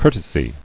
[cour·te·sy]